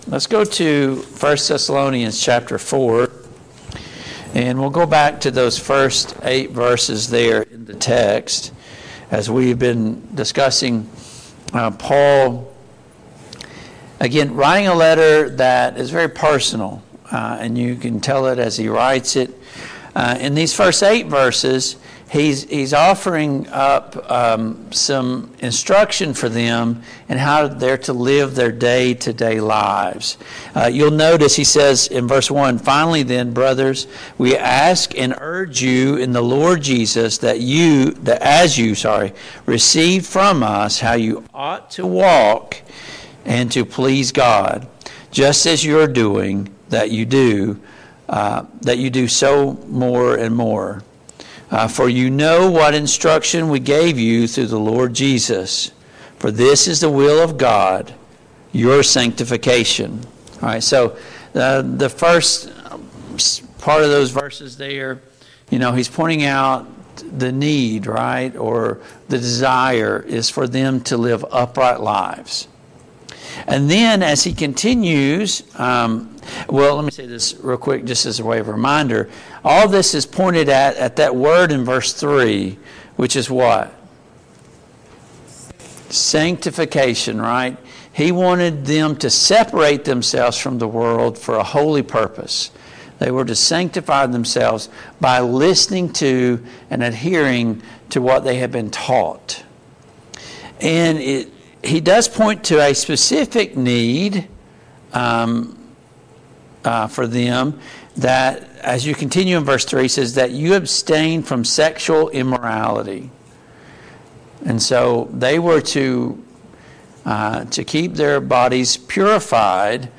Passage: 1 Thessalonians 4:1-18 Service Type: Mid-Week Bible Study